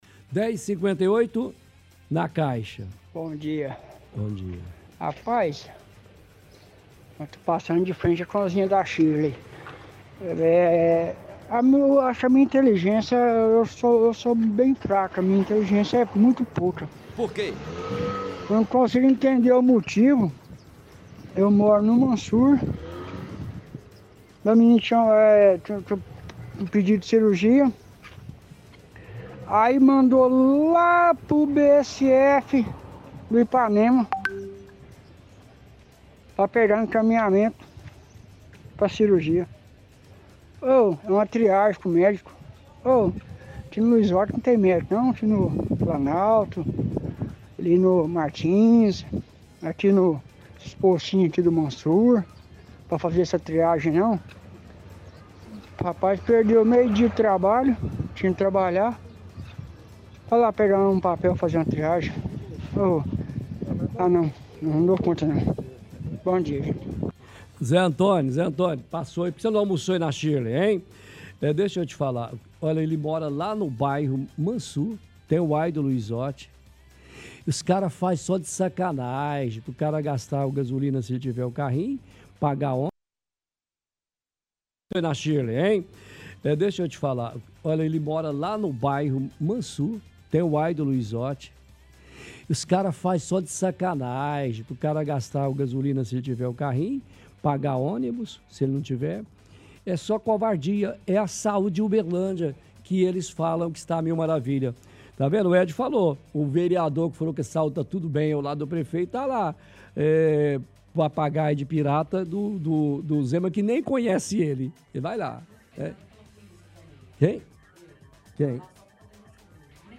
– Ouvinte reclama ao contar que mora no Mansour mas marcaram consulta de triagem para ele no bairro Ipanema.